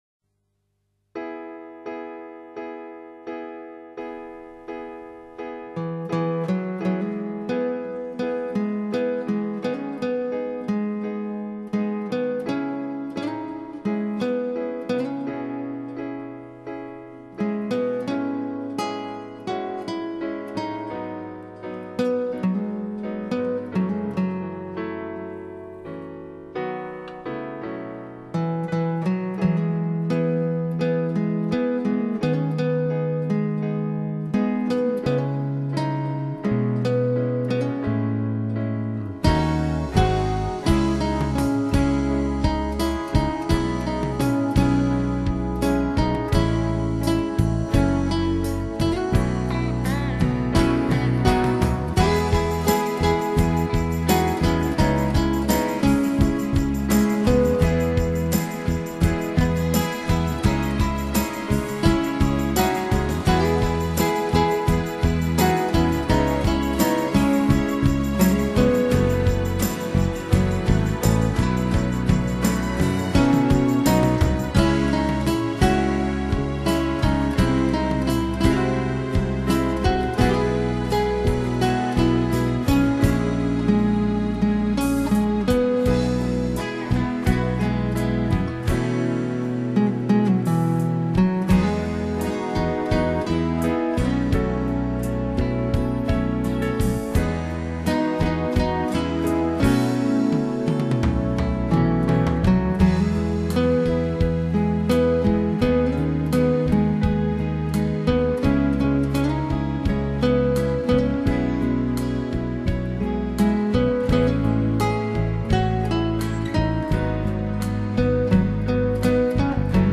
Genre: Instrumental, New Age, Smooth Jazz, Easy Listening